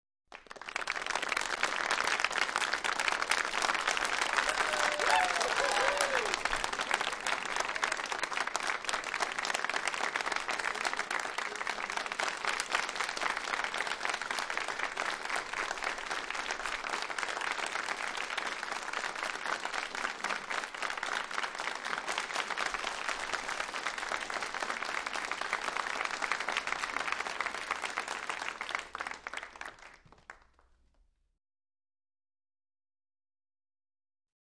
aaaaa- crowd applause
Tags: funny movie quote sound effect